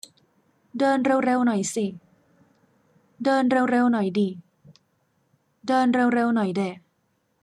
17. สิ [sì] > ดิ [dì] > เดะ [dè]